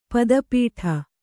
♪ pada pīṭha